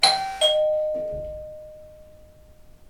dingdong1
bell door house sound effect free sound royalty free Sound Effects